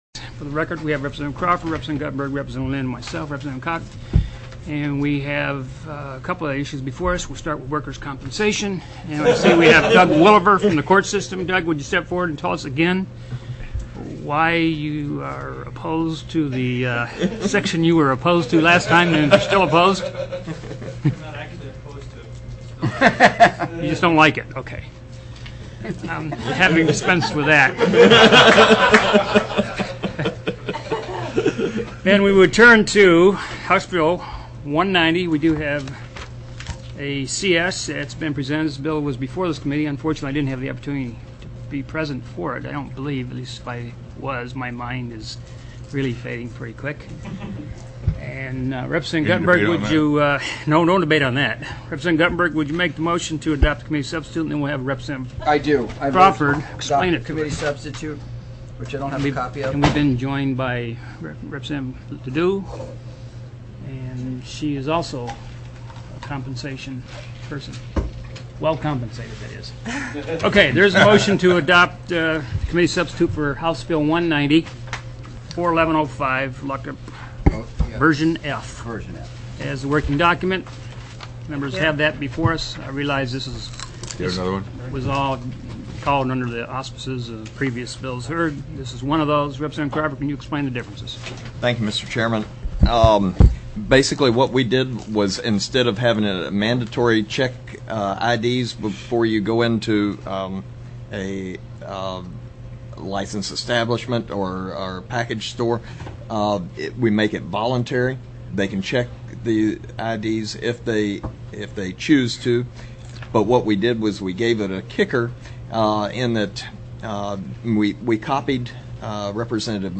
04/20/2005 03:15 PM House LABOR & COMMERCE